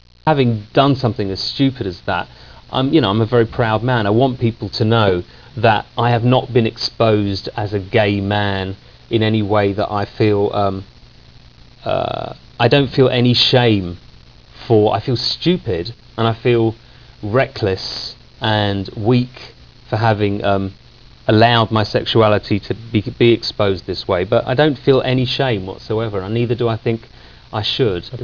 A clip from the CNN interview...